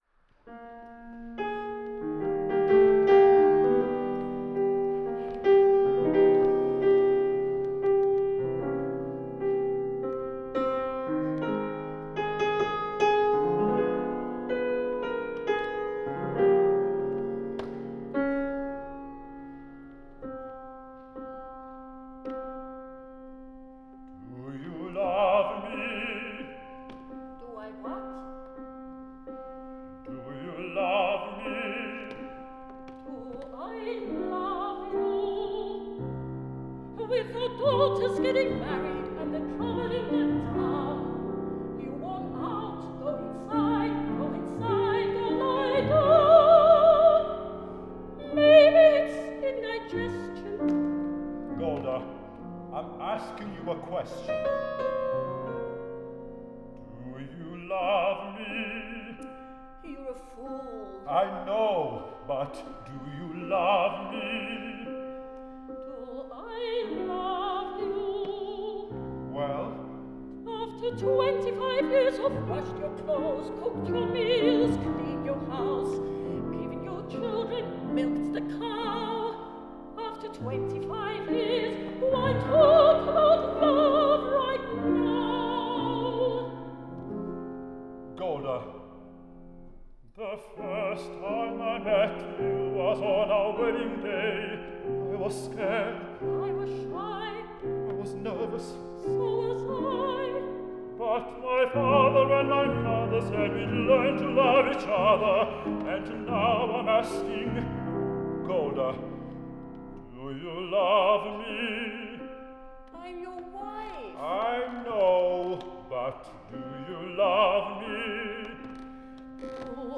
Lunch hour concert 2019